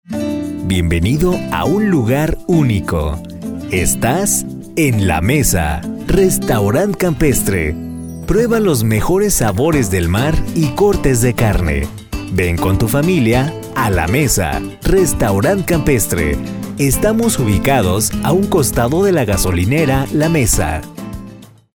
Gracias al tono que manejo (voz media), puedo ofrecer diferentes estilos y tonos para darle calidad a tu proyecto.
Sprechprobe: Werbung (Muttersprache):
Spot - La mesa Restaurant 2.mp3